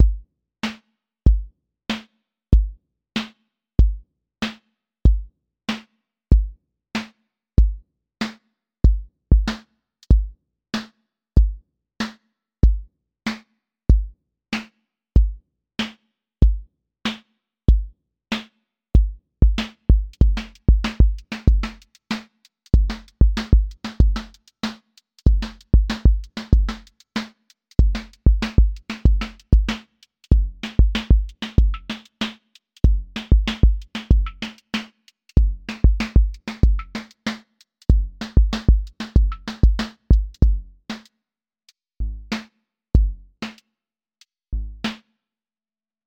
QA Listening Test boom-bap Template: boom_bap_drums_a
Build a boom bap beat at 90 BPM where all melodic/harmonic content comes from additive synthesis — stacked detuned sines via Klang forming warm chord pads with audible beating. Kick and snare stay percussive, but the tonal voices are pure sine clusters: a 5-partial chord pad with slow amplitude modulation, and a 3-partial bass voice with slight detuning from root. The beating frequencies between near-unison partials become the implied rhythm beneath the drum pattern.